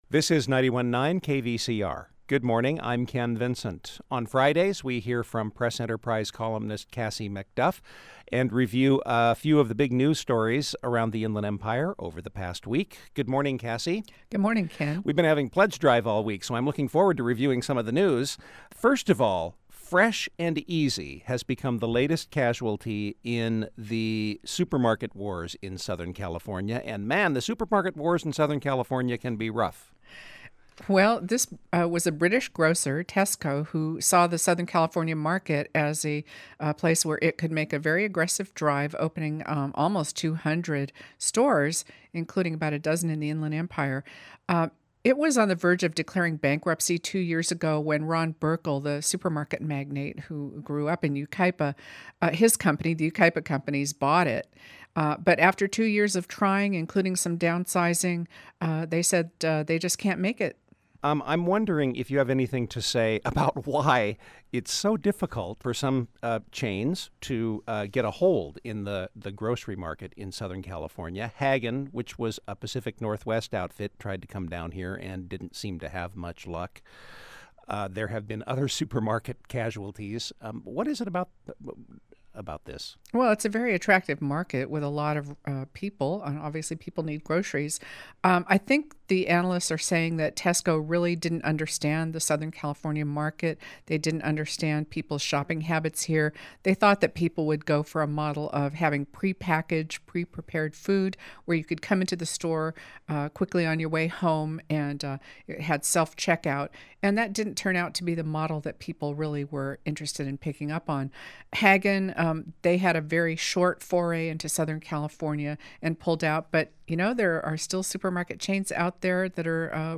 Local Civic Affairs